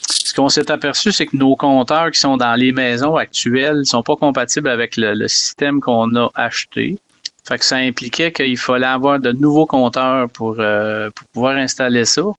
Le maire, Claude Lefebvre, a résumé ce qui s’est passé dernièrement.